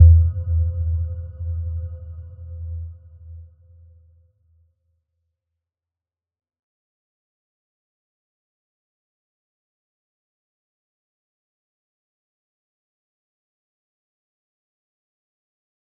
Little-Pluck-E2-mf.wav